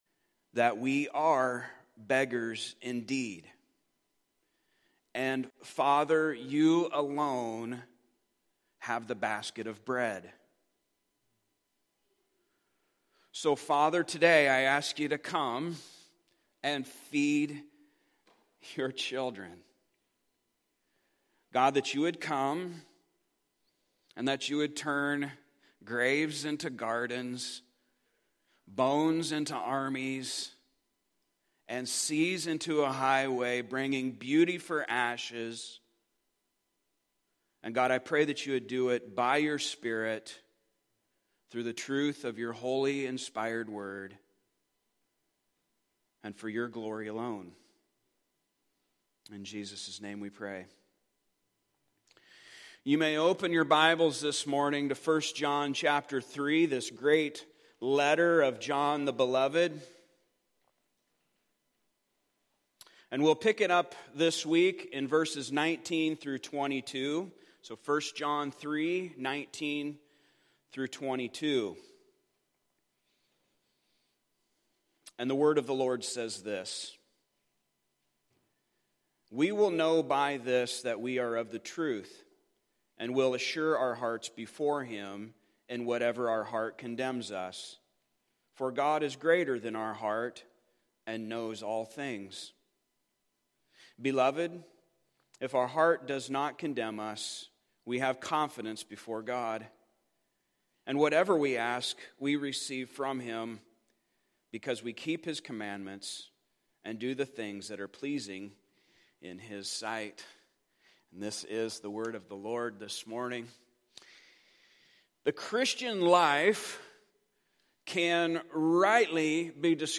Service Type: Sunday Morning Topics: Christian Life , Gospel , Love « Love and the Child of God We in Him